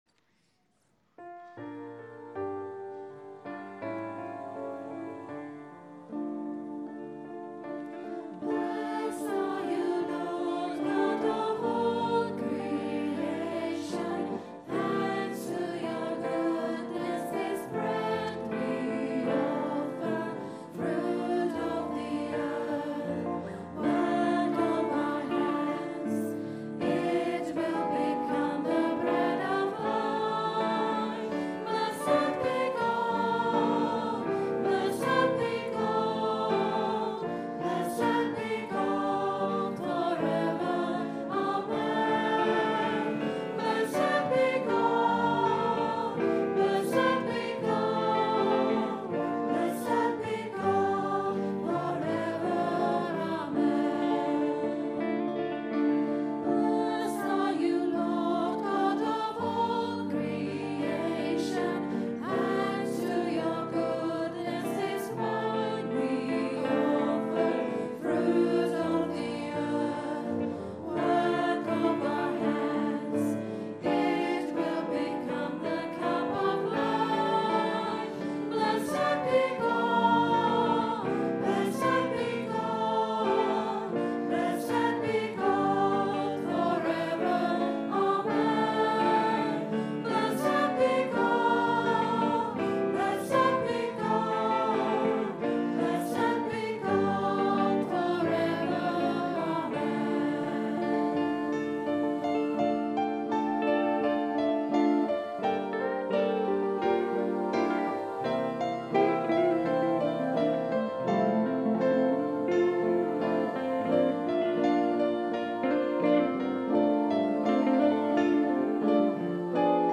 Recorded on a Zoom H4 digital stereo recorder at 10am Mass Sunday 12th December 2010.